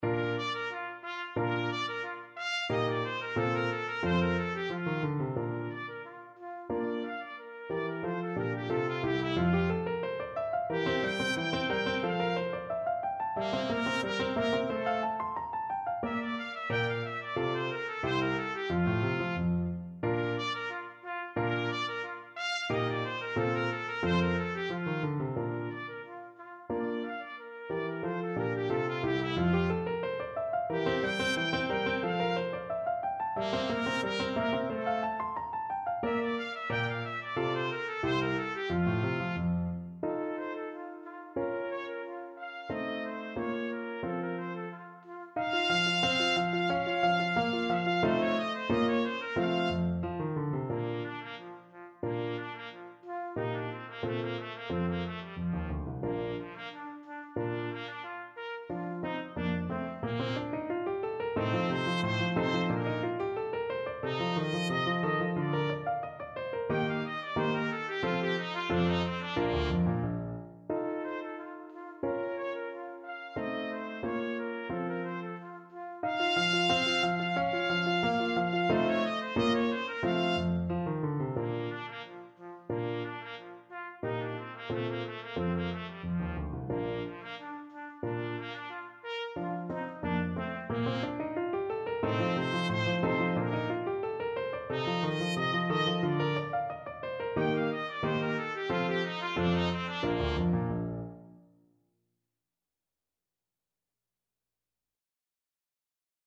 Trumpet
2/2 (View more 2/2 Music)
Spiritoso Spiritoso = 180
F4-G6
Bb major (Sounding Pitch) C major (Trumpet in Bb) (View more Bb major Music for Trumpet )
Classical (View more Classical Trumpet Music)
clementi_sonatina_1_op_36_TPT.mp3